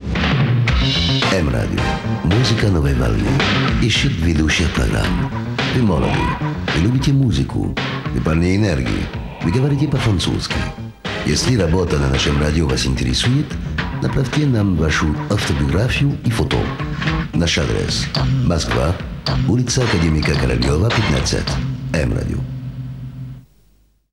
Архив М-Радио Оформление
Объявление о поиске ведущих (М-Радио, 1990-е)